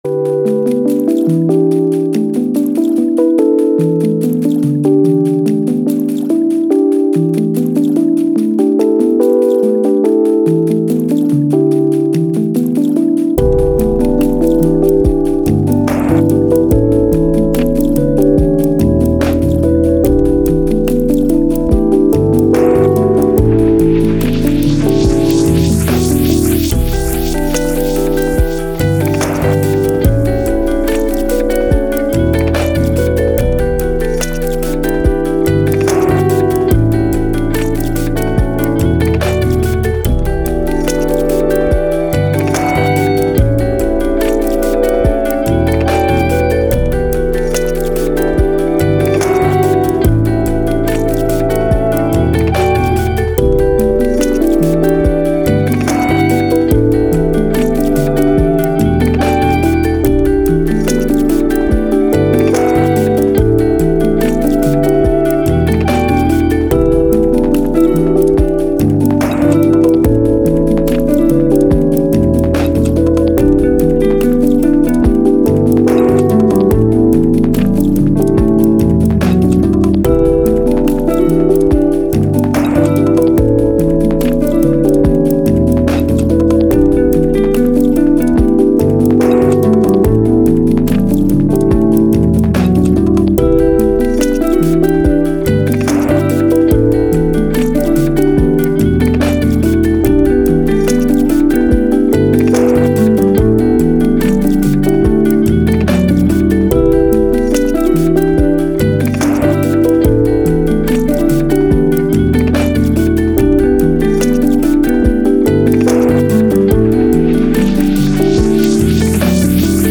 Downtempo, Chilled, Thoughtful, Story